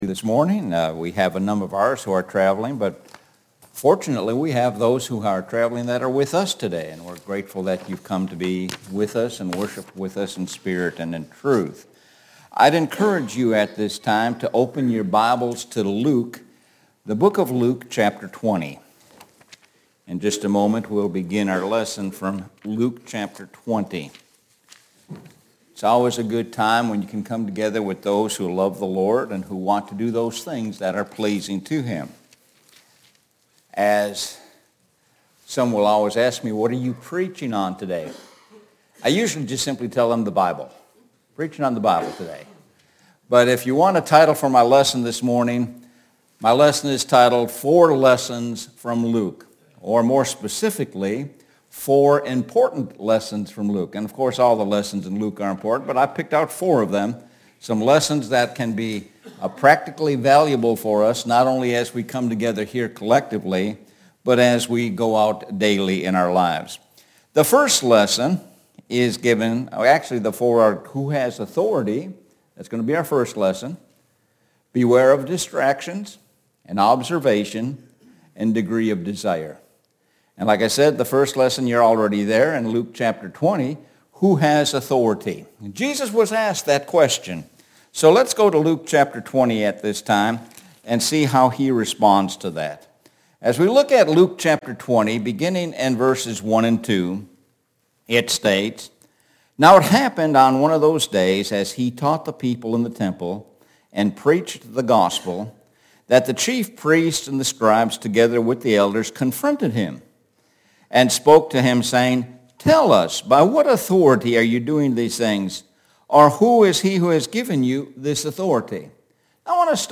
Lakeland Hills Blvd Church of Christ